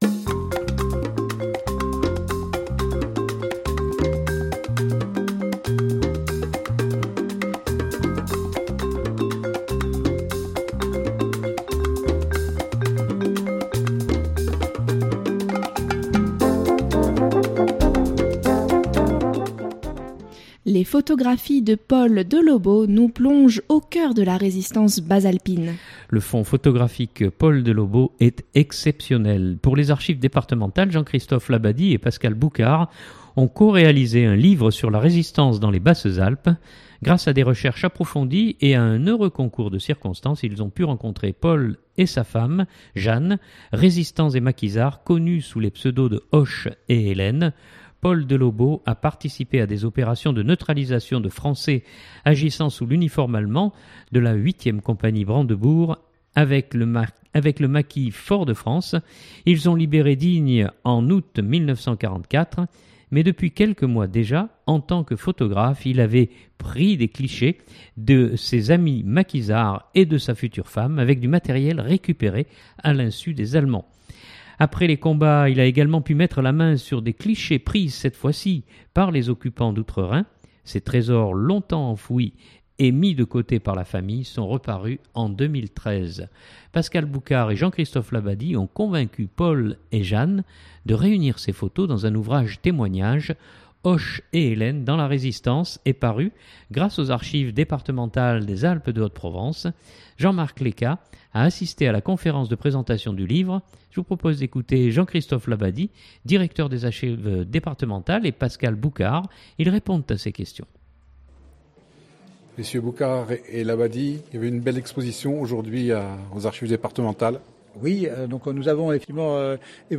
ils répondent à ses questions